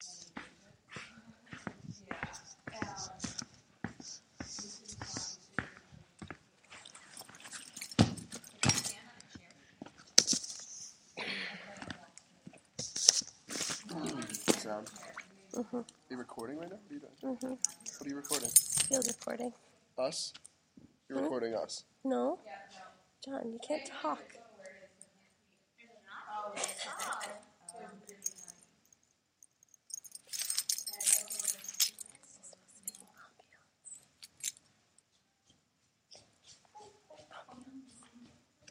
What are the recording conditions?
Location: Black Box Theater, Hofstra University